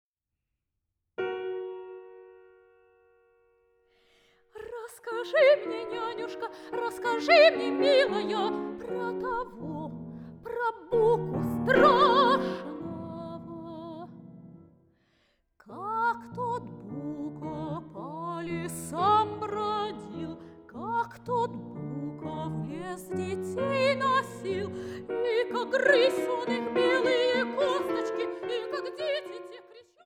Sopranistin
Pianistin